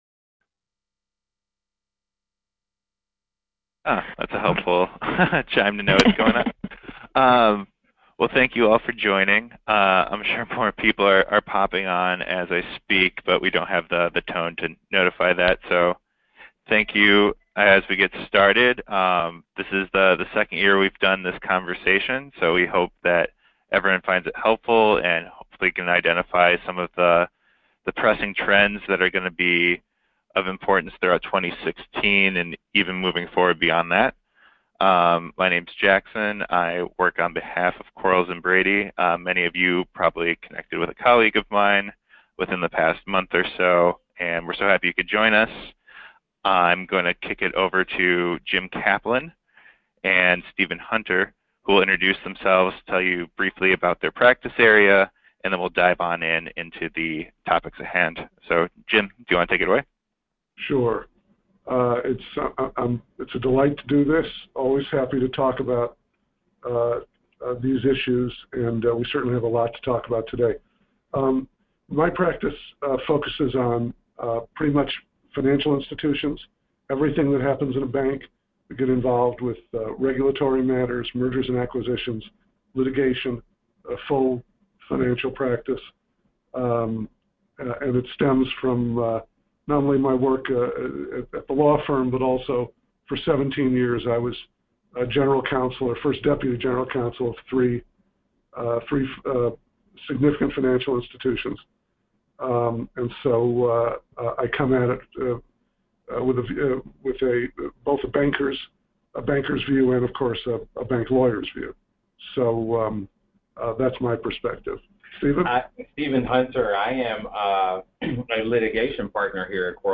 Members of Quarles & Brady's Financial Services Industry Team hosted a roundtable discussing potential challenges for 2016 including the Dodd-Frank Act, the Federal Reserve, and loans.